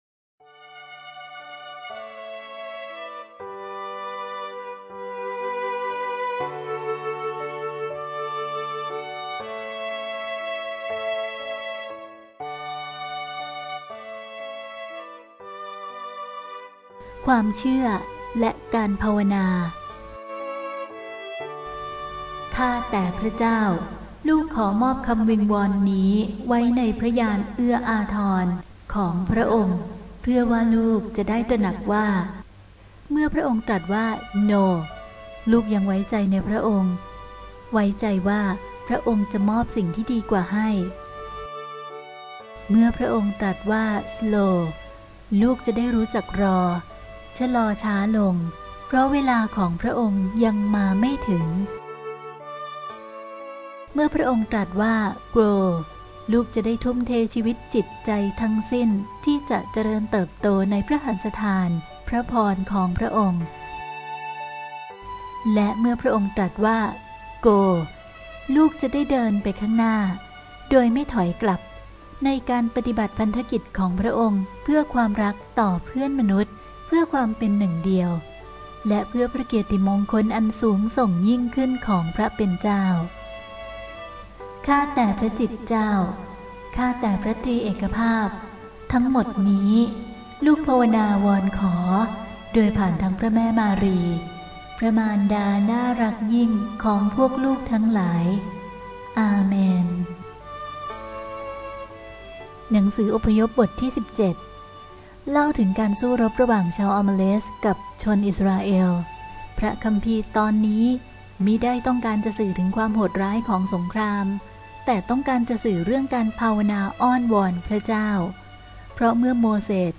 เสียงอ่านโดย